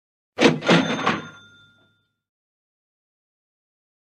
Cha Ching money Sound Effect sound effects free download